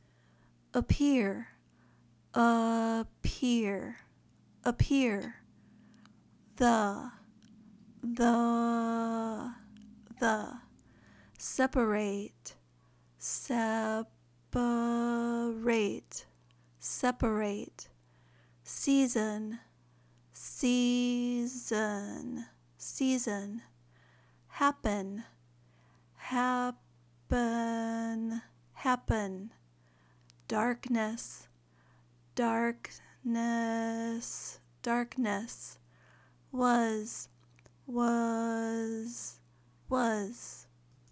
Learn the Schwa Sound
Here are more words with the schwa sound from today’s lesson.
Practice Schwa Words